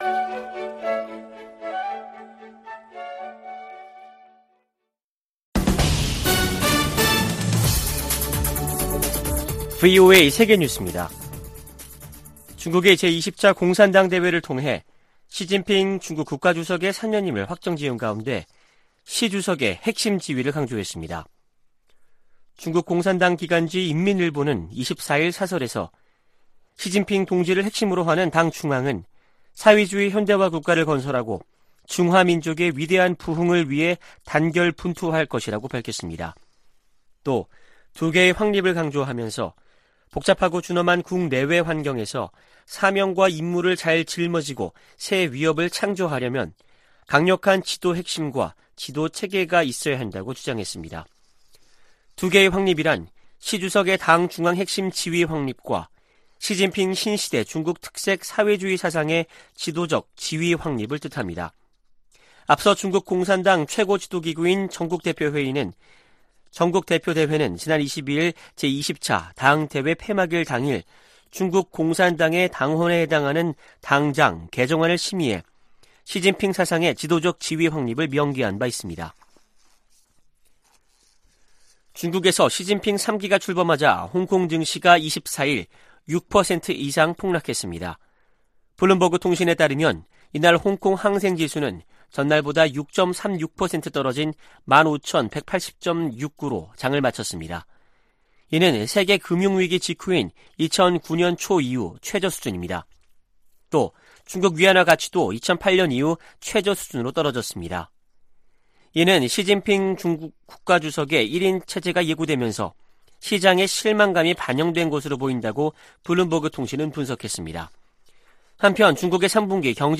VOA 한국어 아침 뉴스 프로그램 '워싱턴 뉴스 광장' 2022년 10월 25일 방송입니다. 한국 합동참모본부는 24일 서해 백령도 서북방에서 북한 상선이 북방한계선(NLL)을 침범해 경고 통신과 경고사격으로 퇴거 조치했다고 밝혔습니다. 북대서양조약기구는 한국과 함께 사이버 방어와 비확산 등 공통의 안보 도전에 대응하기 위해 관계를 강화하는데 전념하고 있다고 밝혔습니다. 국제자금세탁방지기구가 북한을 11년째 대응조치를 요하는 '고위험 국가'에 포함했습니다.